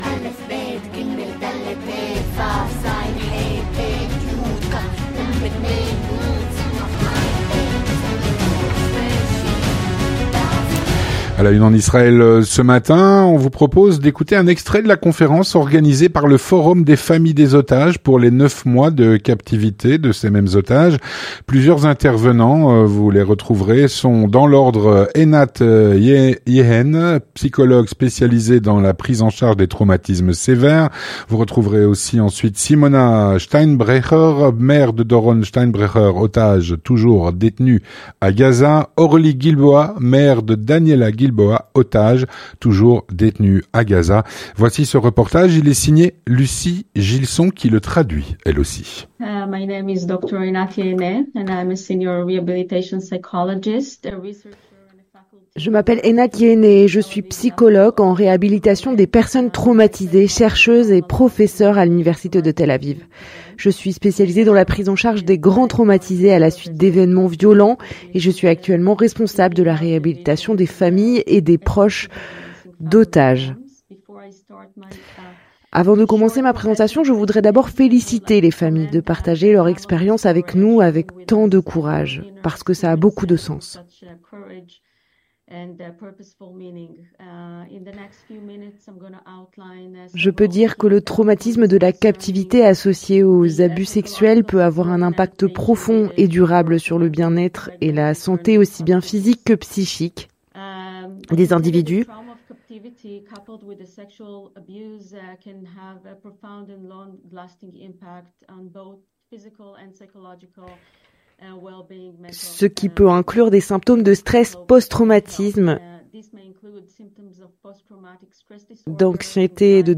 Extraits de la conférence organisée par le Forum des familles des otages pour les 9 mois de captivité des otages.